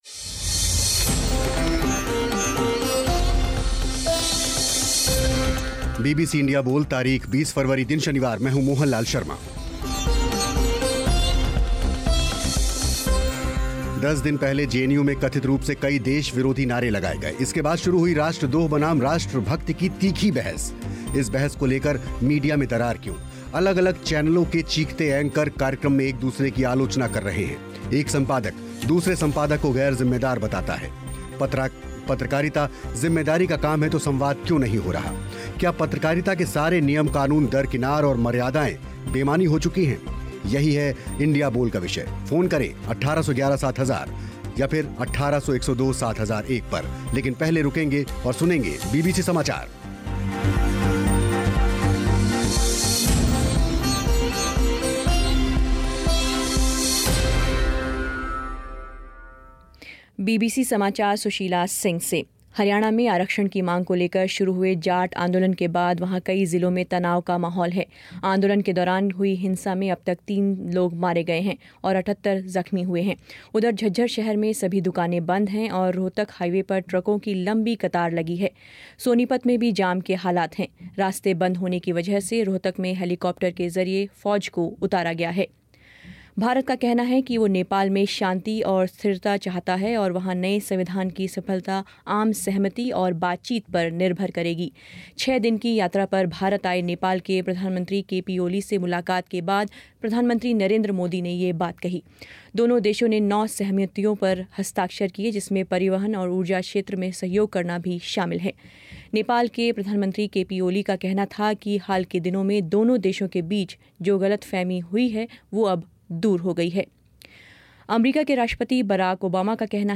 क्या पत्रकारिता के सारे नियम-क़ानून दरकिनार और मर्यादाएँ बेमानी हो चुकी हैं? इंडिया बोल में इसी पर हुई बहस कार्यक्रम में हिस्सा लिया एनडीटीवी के वरिष्ठ पत्रकार रवीश कुमार और आप श्रोताओं ने